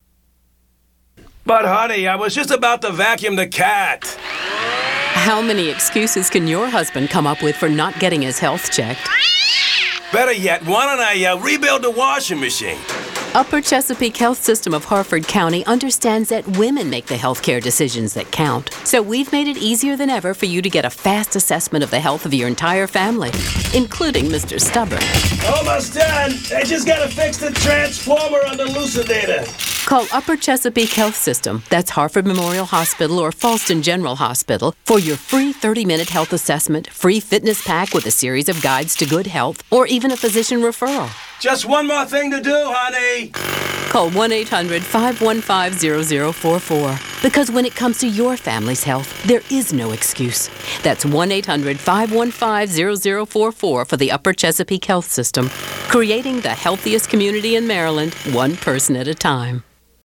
We also write and produce radio spots that do more than paint pictures.
:60 spot for UCHS
Upper Chesapeake Health System discovered that laughter could be the best medicine to launch their brand new medical facilities. This Addy-winning spot takes the wife’s side as her husband comes up with every excuse in the book to avoid a medical exam.